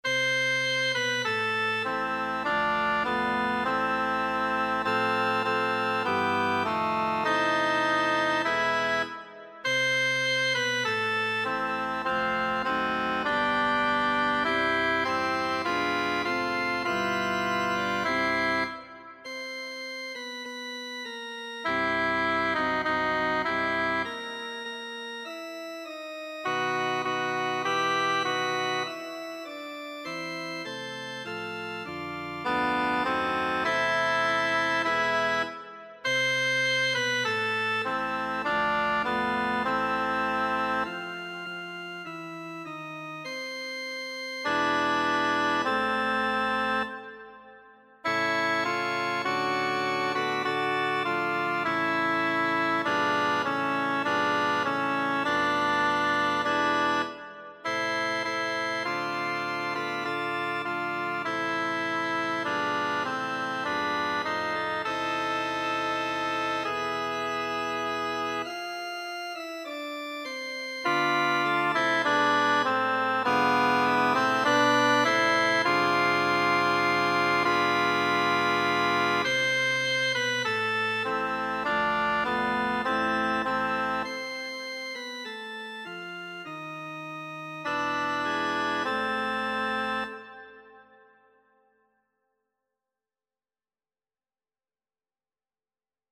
Alt
abendlich-schon-rauscht-der-wald-alt.mp3